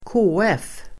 Uttal: [²k'å:ef:]